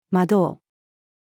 惑う-female.mp3